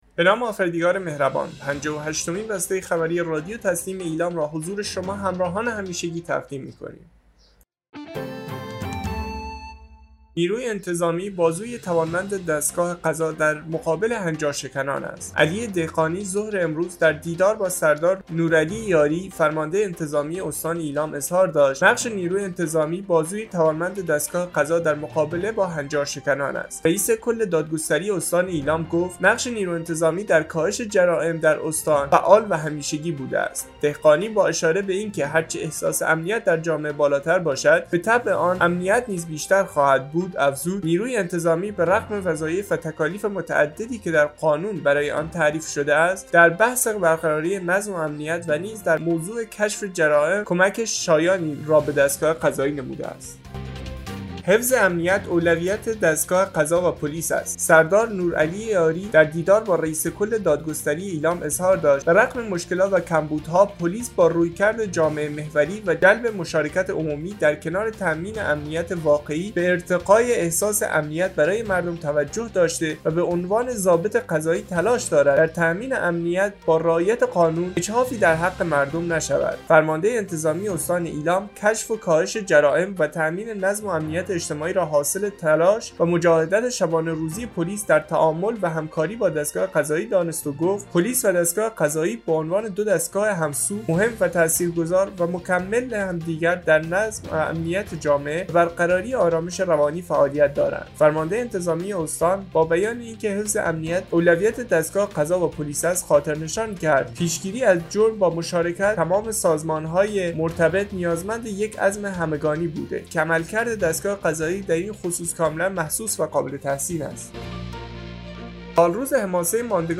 به گزارش خبرگزاری تسنیم از ایلام, پنجاه و هشتمین بسته خبری رادیو تسنیم استان ایلام با عنا‌وین خبری چون سالروز حماسه ماندگار آزادسازی مهران و فتح میمک ثبت ملی می‌شود،حفظ امنیت، اولویت دستگاه قضا و پلیس است و نیروی انتظامی بازوی توانمند دستگاه قضا در مقابل هنجارشکنان است منتشر شد.